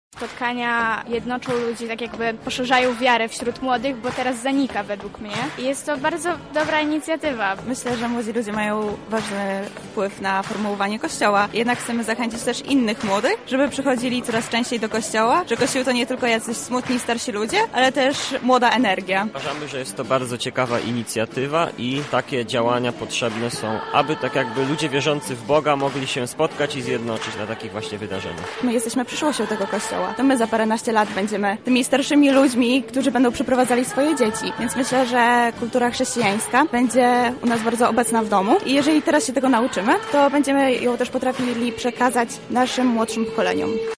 Zapytaliśmy uczestników spotkania o wyzwania i problemy z którymi muszą się mierzyć młodzi katolicy w dzisiejszych czasach: